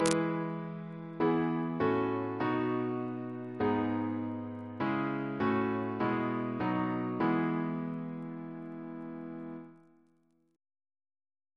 Single chant in E Composer: Philip Hayes (1738-1797) Reference psalters: ACB: 44; RSCM: 182